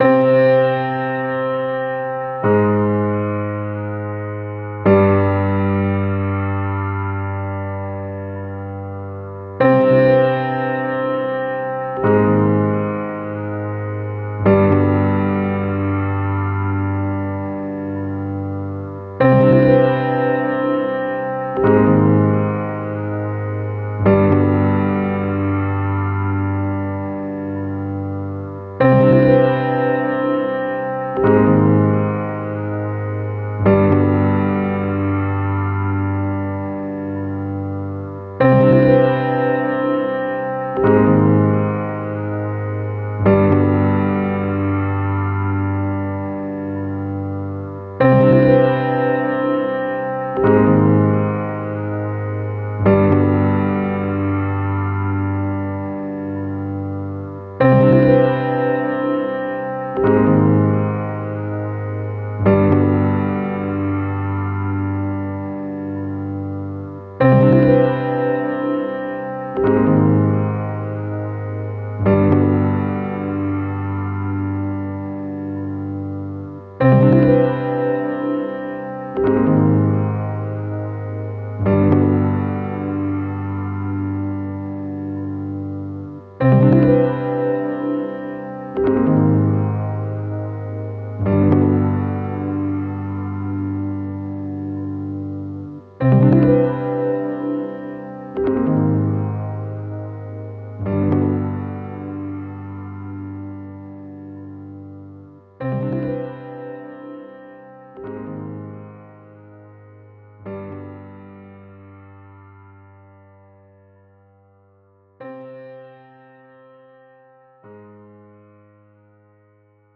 I Was Trying To Make A Calm Approach to It, But I Don’t Know If It’s Great.
The chords that I could hear was too dull and could use some melody on the 4th or 5th octave.
The chord progression is quite basic, you could alternate the chords by inverses or even add an extra note on 7, 9 or 11 after the root.
I guess I was trying to make music for horror or medieval game.